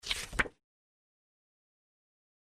paper1.mp3